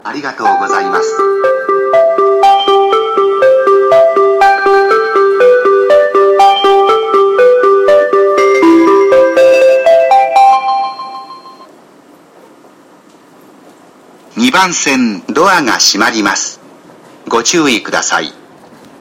この駅の発車メロディーは１番線が「春」で２番線が「せせらぎ」が使用されています。スピーカーはユニペックス小丸型が使用されています。 音質は結構いいほうですね。
２番線JY：山手線
発車メロディーこちらは余韻切りです。